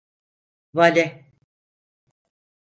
vale a 🐌 Significado Conceptos Sinónimos Traducciones Notes Extra tools (Inglés) valet jack Frecuencia C2 Pronunciado como (IPA) /ˈva.le/ Etimología (Inglés) Borrowed from French valet.